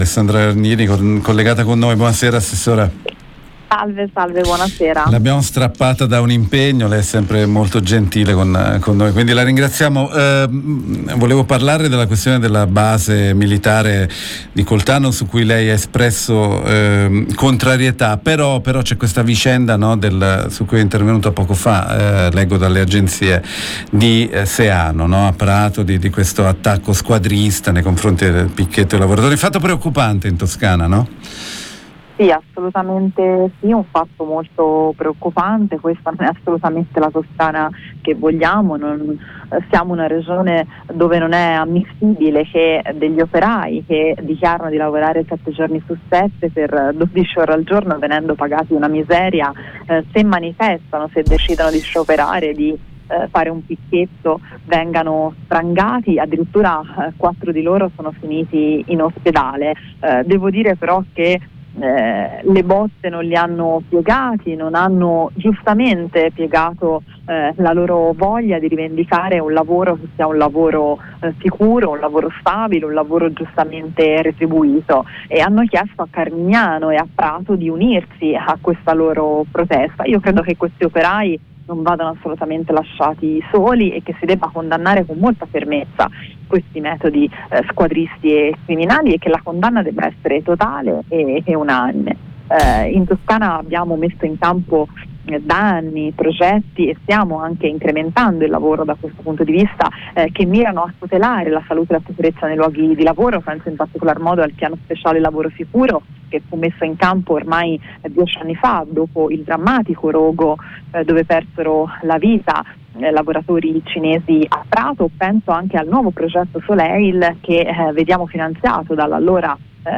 Lo afferma in una nota l’assessora regionale Alessandra Nardini, riferendosi all’ipotesi di costruire una parte della base nell’area Cisam, a San Piero a Grado (Pisa) e la restante parte a Pontedera (Pisa), come previsto da un’intesa sottoscritta al tavolo interistituzionale anche dalla Regione Toscana. L’abbiamo intervistata